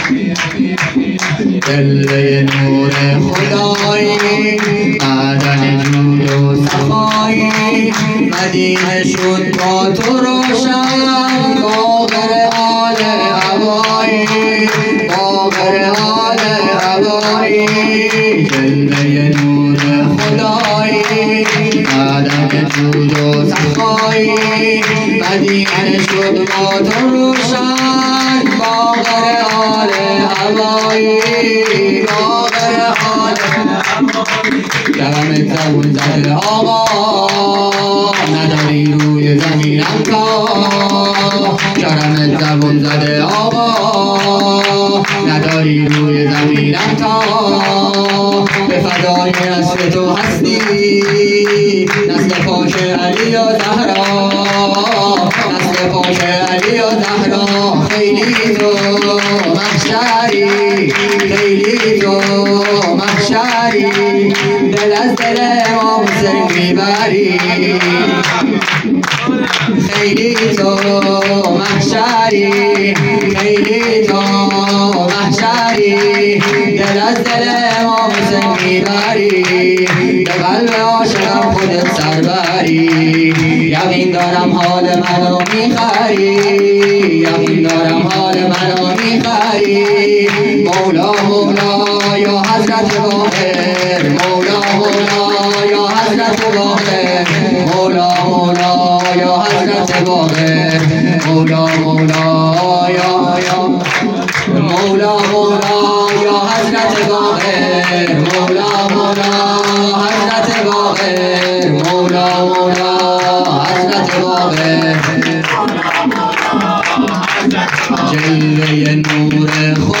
سرود
ولادت امام باقر(ع)